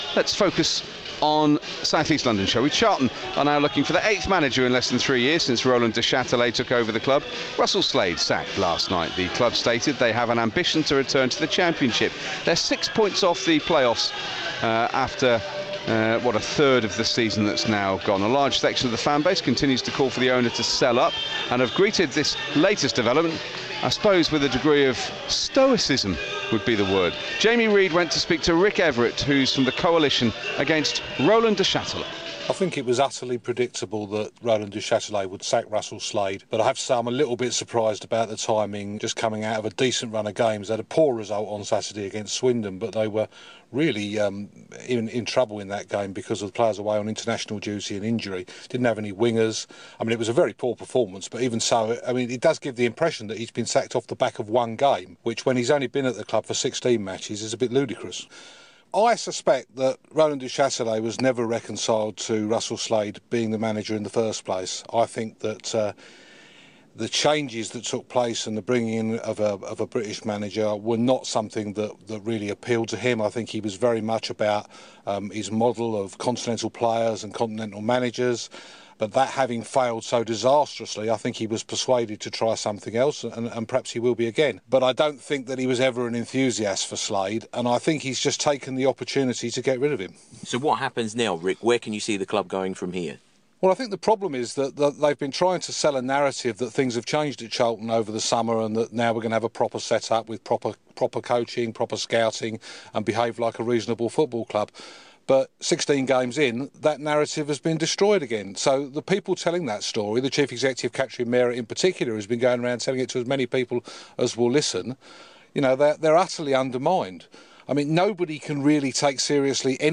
Featuring an interview